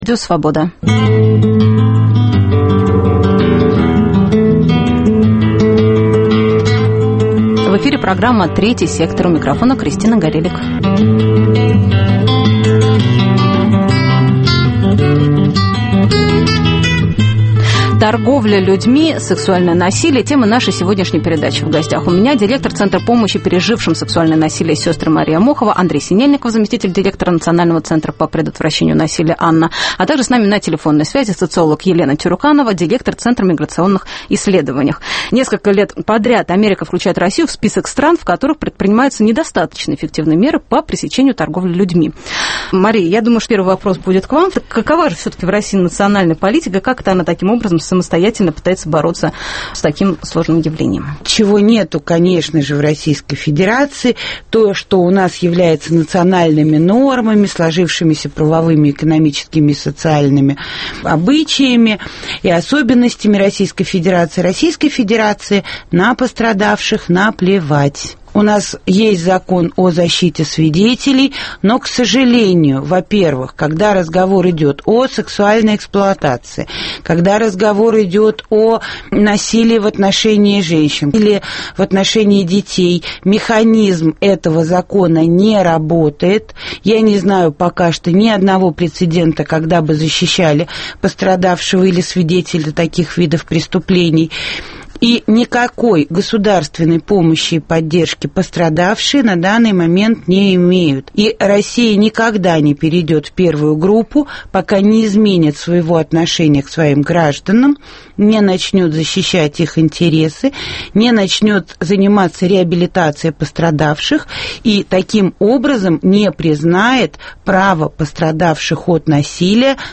Репортаж из колонии для несовершеннолетних под Можайском. Людмила Алексеева о социальной адаптации бывших заключенных.